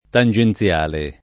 tangenziale [ tan J en ZL# le ]